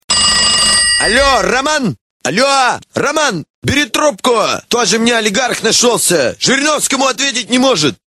Рингтоны пародии